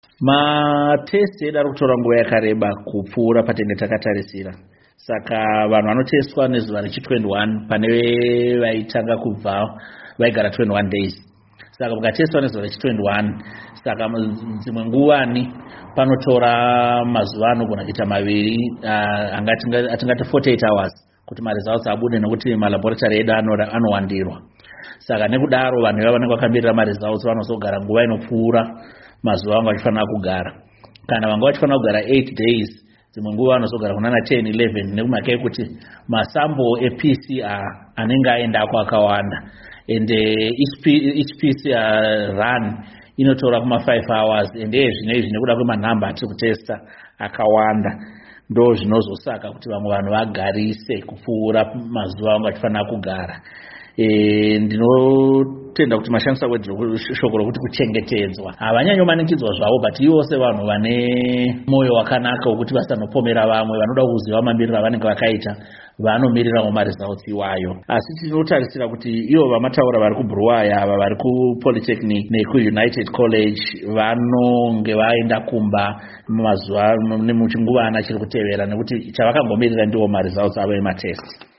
VaNick Mangwana Vachitsanangudza Pamire Hurumende